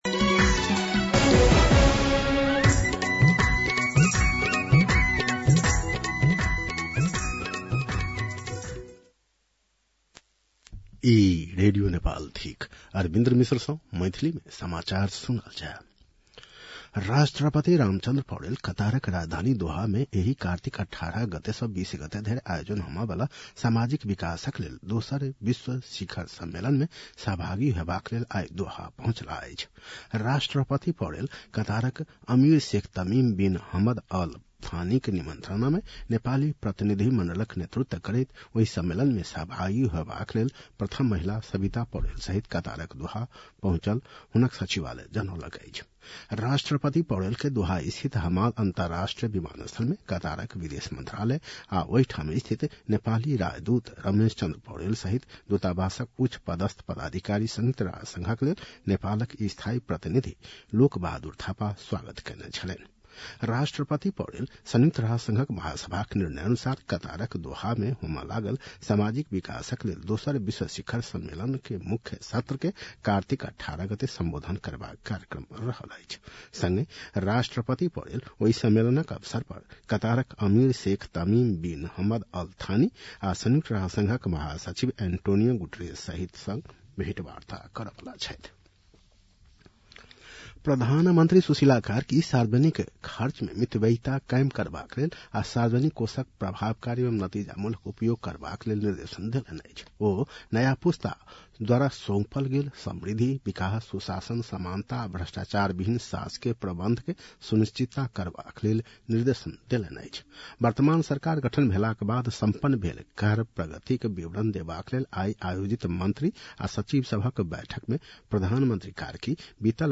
मैथिली भाषामा समाचार : १७ कार्तिक , २०८२
6.-pm-maithali-news-.mp3